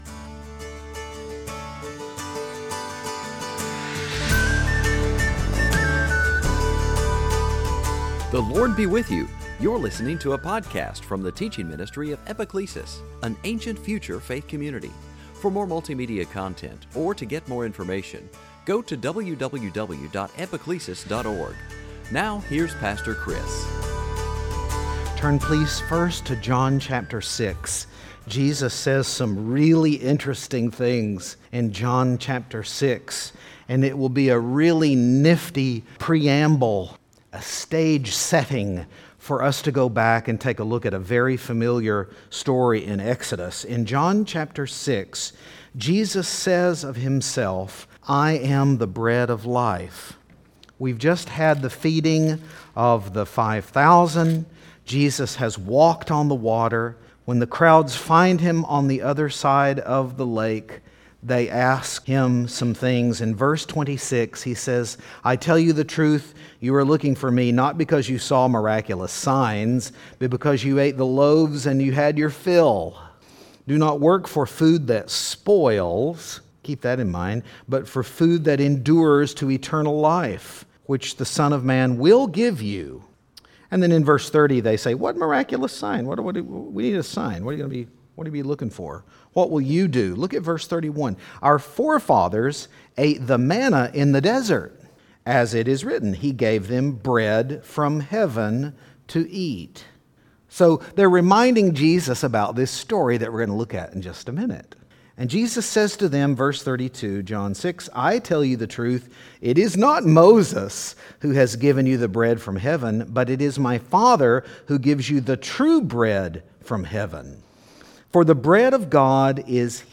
Sunday Teaching You’ve heard the phrase